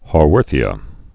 (hô-wûrthē-ə, -thē-)